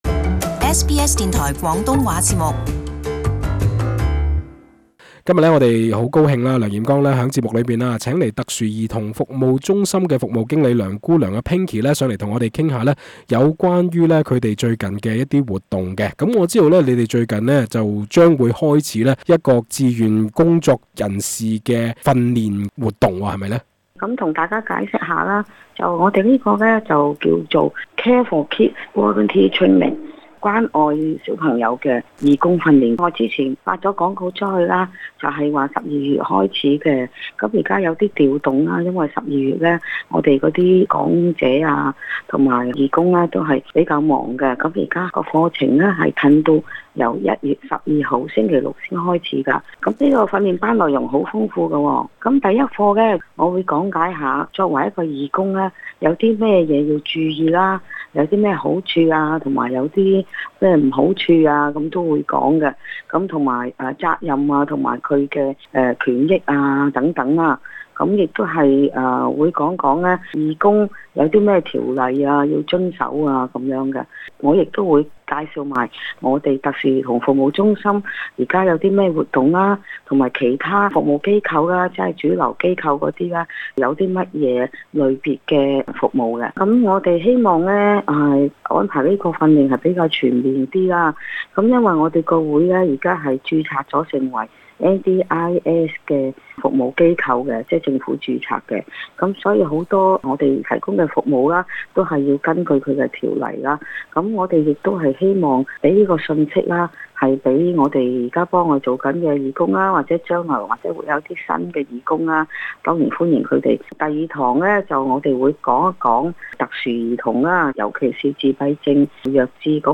【社區專訪】特殊兒童服務中心快將舉辦義工訓練